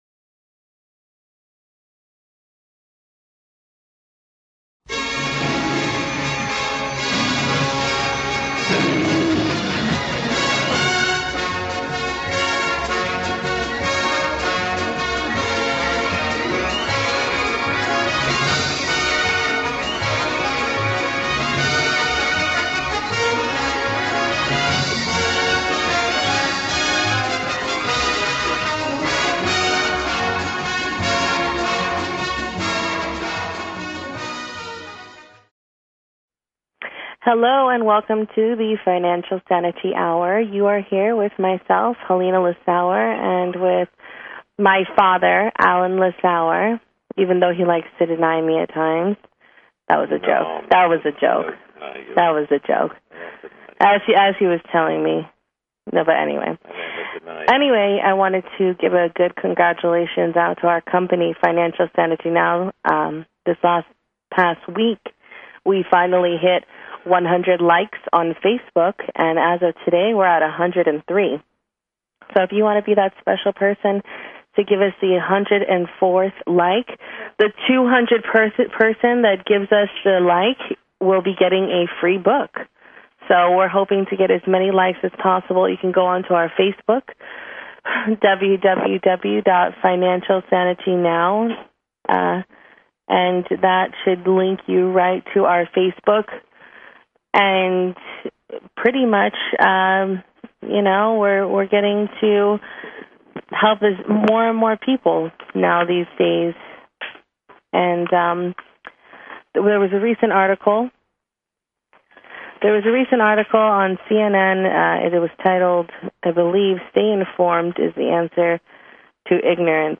Talk Show Episode, Audio Podcast, Financial Sanity Now and Courtesy of BBS Radio on , show guests , about , categorized as